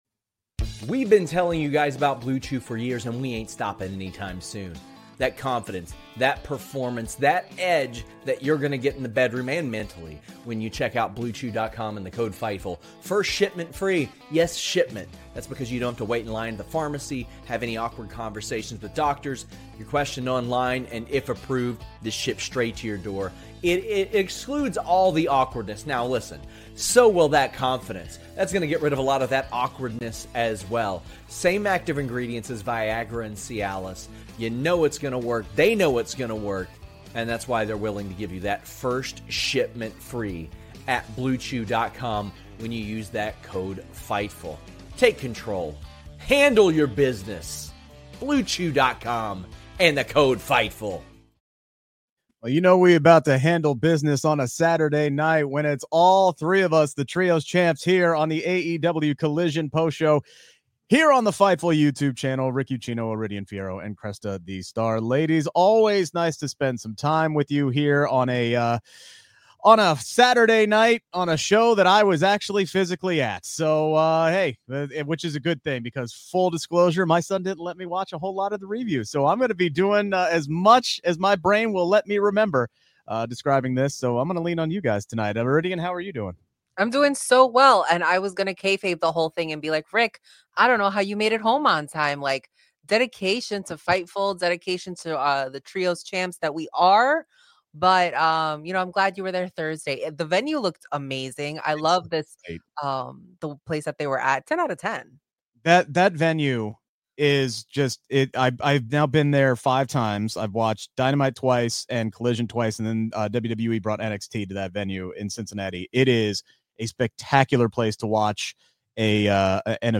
Download - Eric Bischoff On Tony Khan, CM Punk's AEW Issues, WCW In Canada | 2022 Interview | Podbean